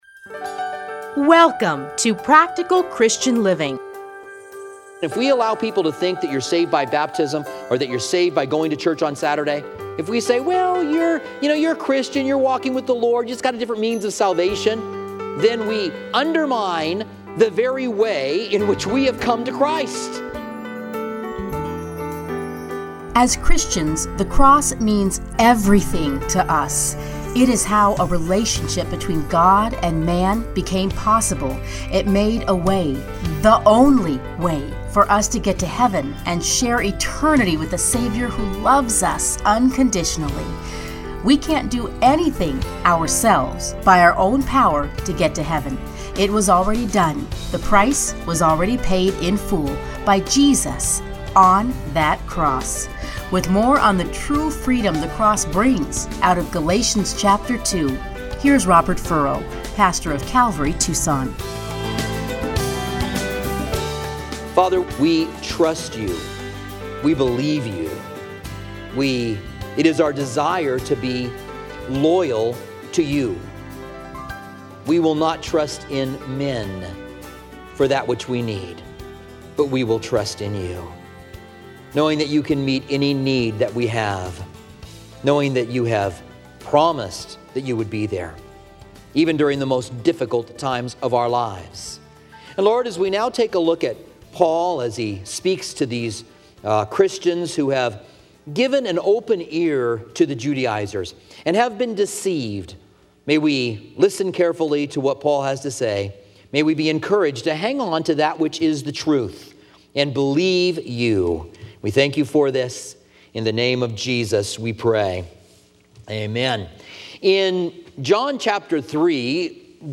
Listen here to his commentary on Galatians.